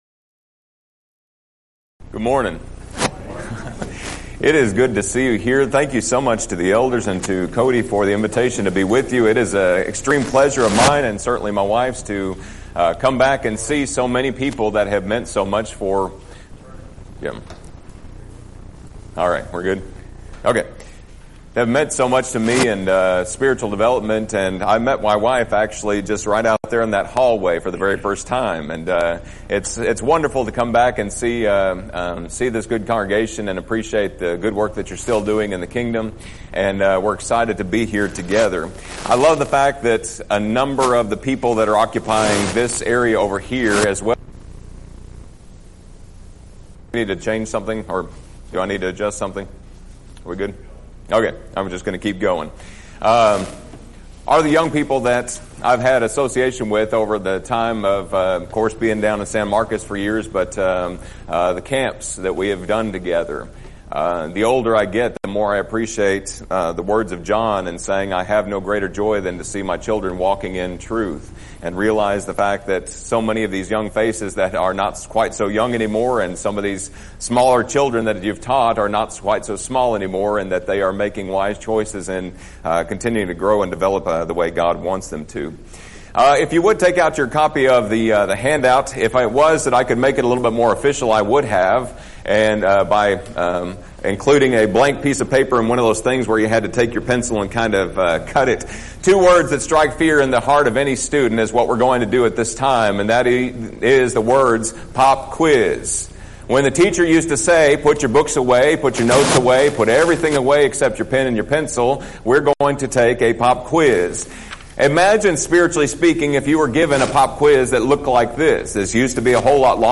Event: 2nd Annual Arise Workshop
lecture